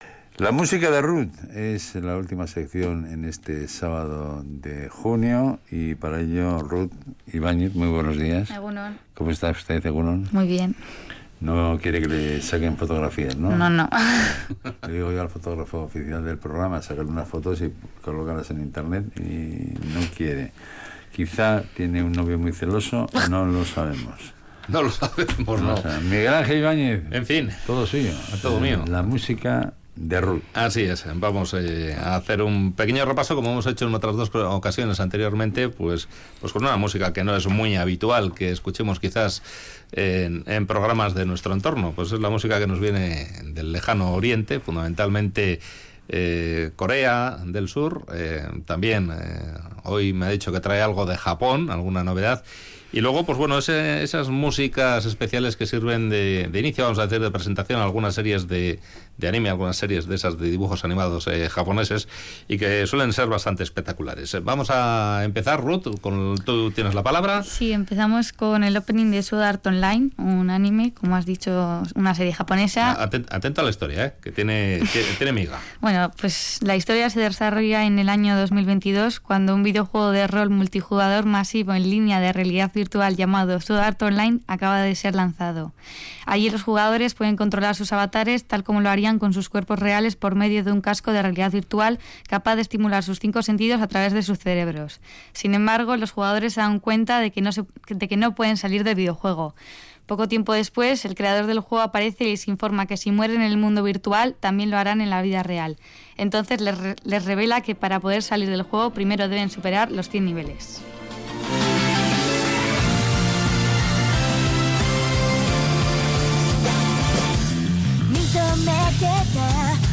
Audio: Escucharemos "openings" de conocidas series de anime, la música de grupos de K-Pop, algunos de los cuales acaban de lanzar sus últimos trabajos, y una muestra de rock japonés